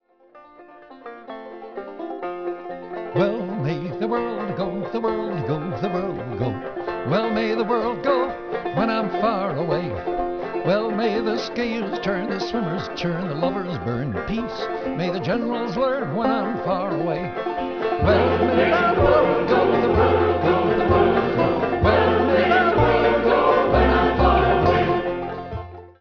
voice, banjo
bass
triangle
chorus